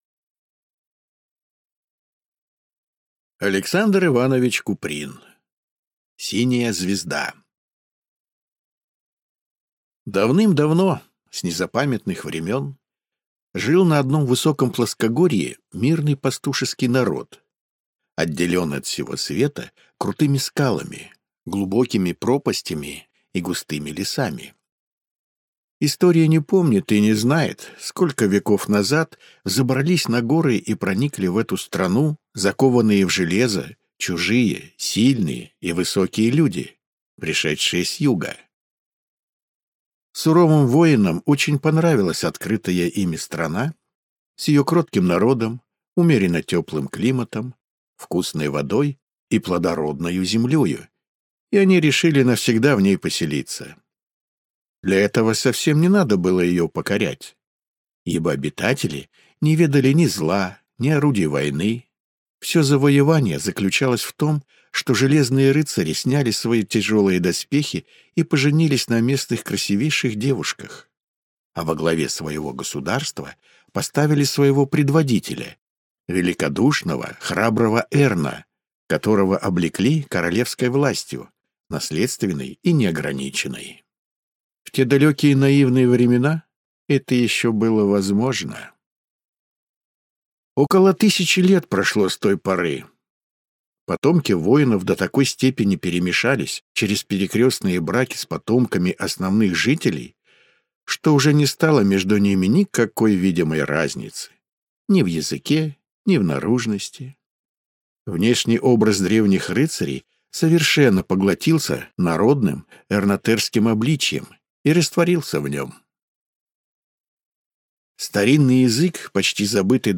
Аудиокнига Синяя звезда | Библиотека аудиокниг